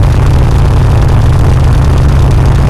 center_charge.wav